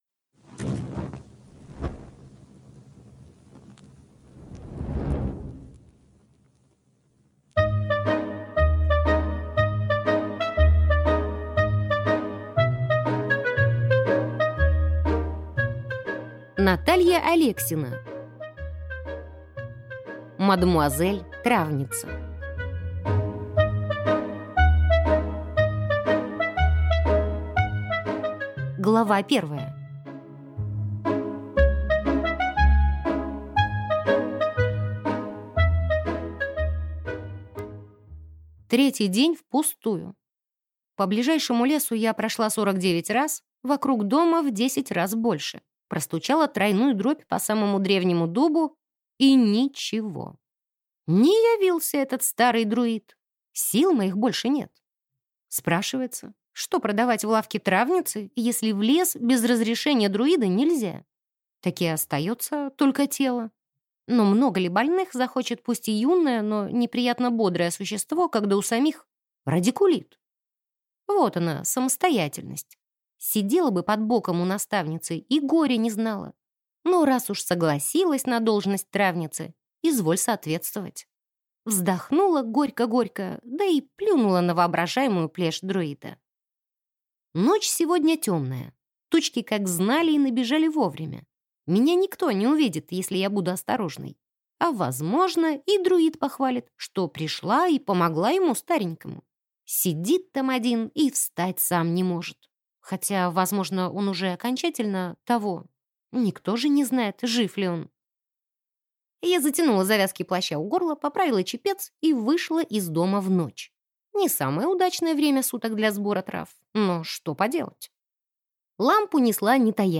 Аудиокнига Мадемуазель травница | Библиотека аудиокниг